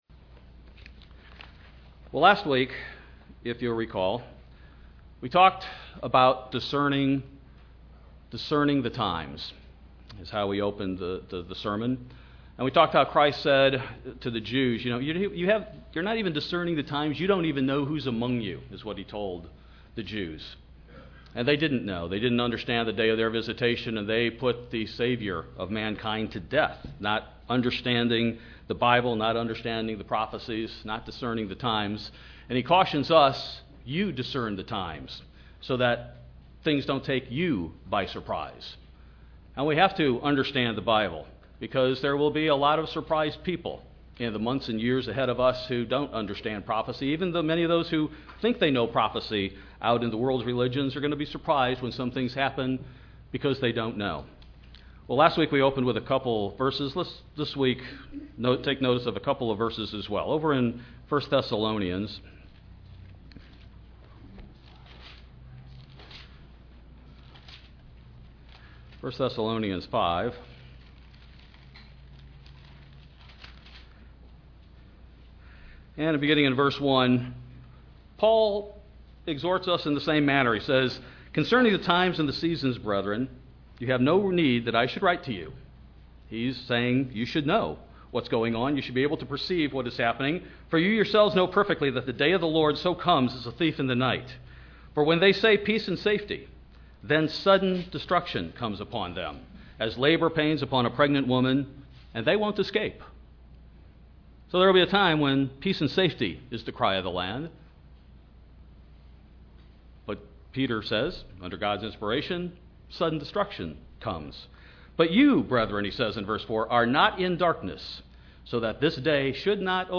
This sermon will discuss one of those key components we must understand, from the Biblical perspective, so we won't be taken by surprise by things that may "shortly come to pass."